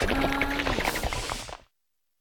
Cri de Vrombi dans Pokémon Écarlate et Violet.